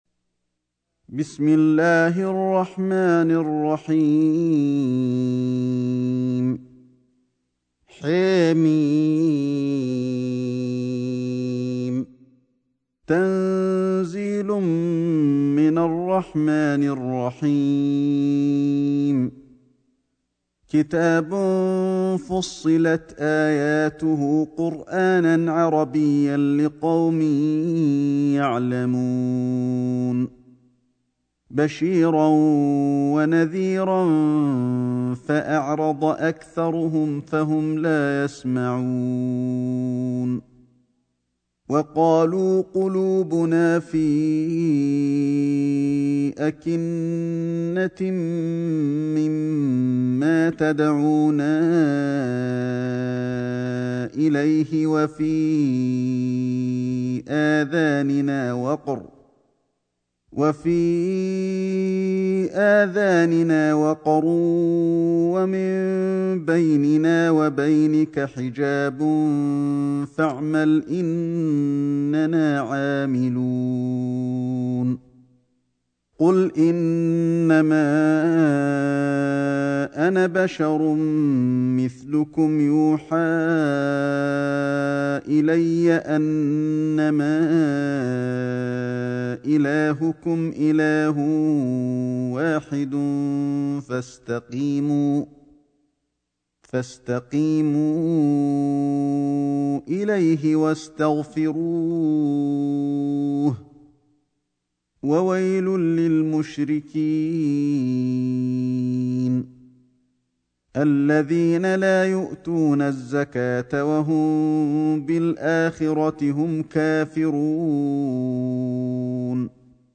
سورة فصلت > مصحف الشيخ علي الحذيفي ( رواية شعبة عن عاصم ) > المصحف - تلاوات الحرمين